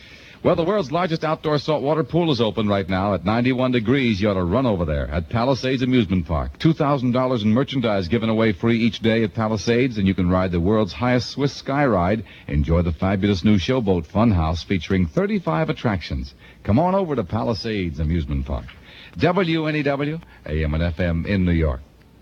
WNEW commercial